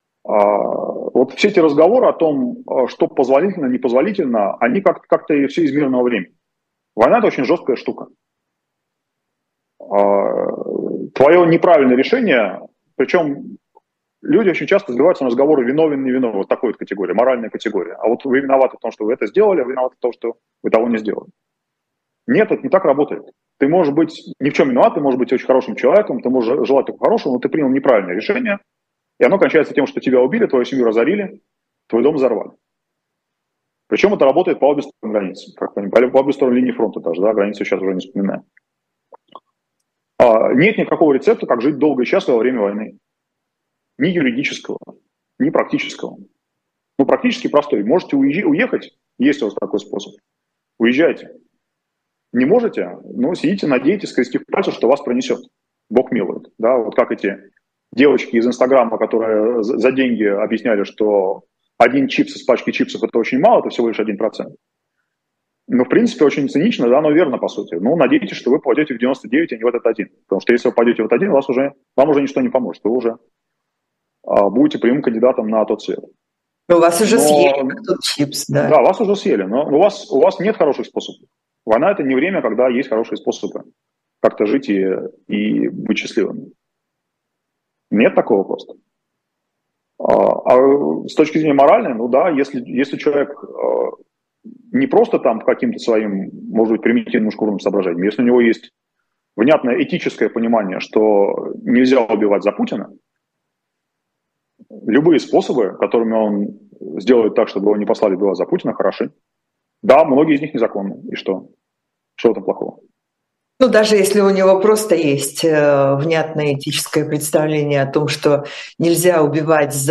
Фрагмент эфира от 24.11.23